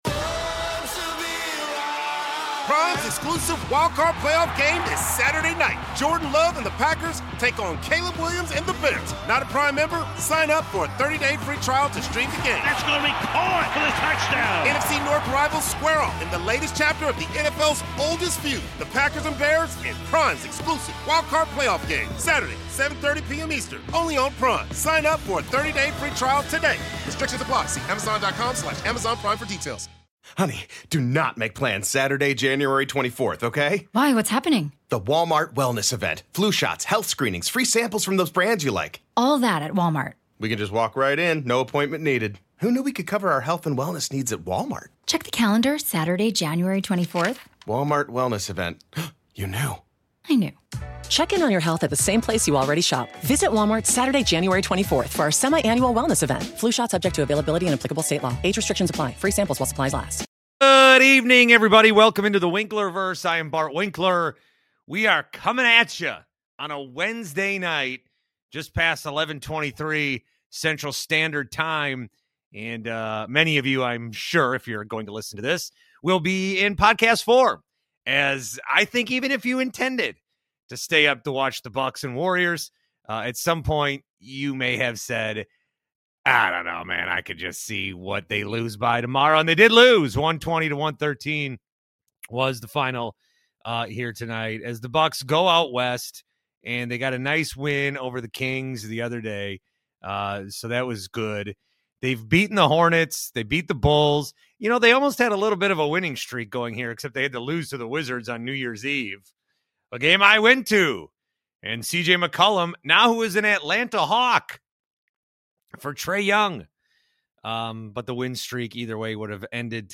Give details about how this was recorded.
We're back live after a Bucks game! A kind of lackluster one though as Milwaukee falls to the Golden State in a game that didn't have as much juice as these matchups normally do.